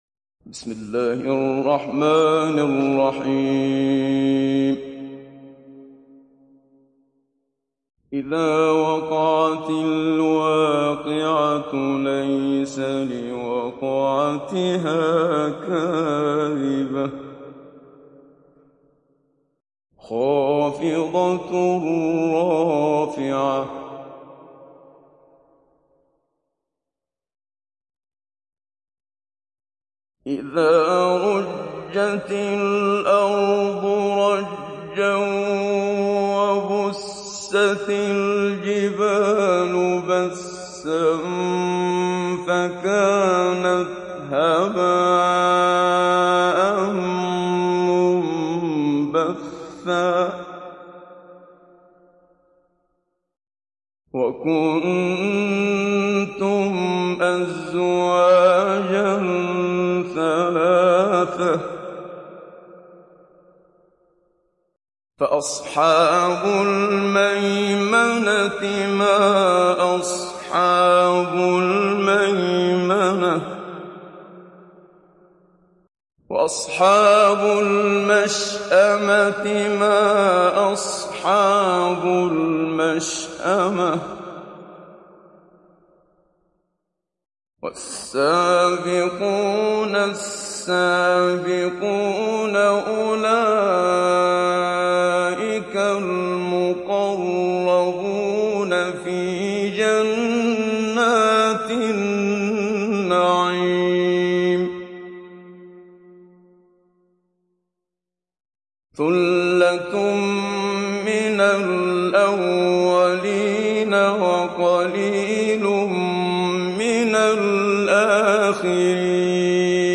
ডাউনলোড সূরা আল-ওয়াক্বি‘আহ Muhammad Siddiq Minshawi Mujawwad